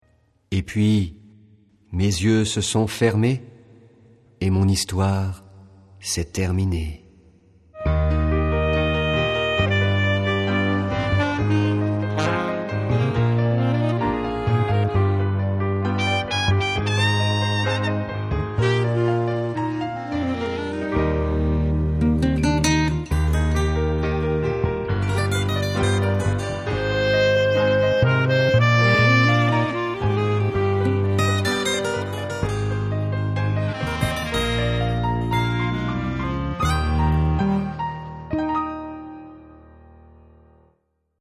Epilogue. Lecture de la dernière partie contée extraite du spectacle pour enfants Swing la Lune.
Lecture du spectacle enfant Swing la Lune.